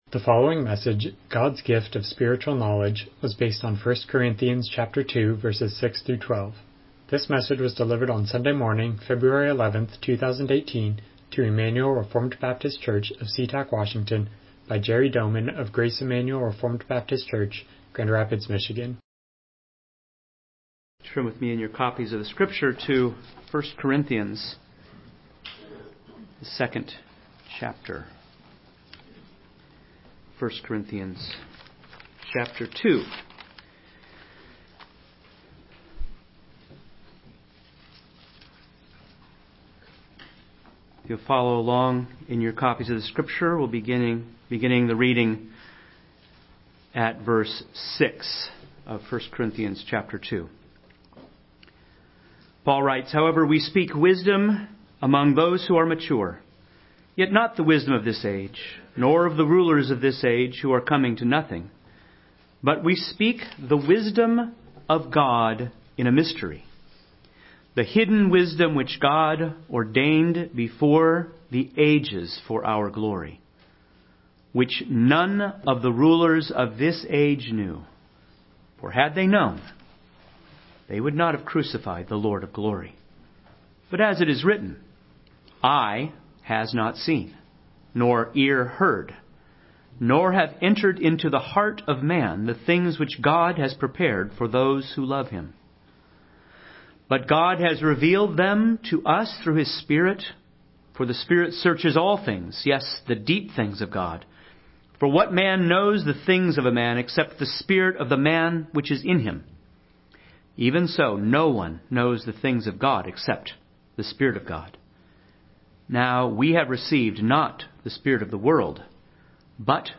Passage: 1 Corinthians 2:6-12 Service Type: Morning Worship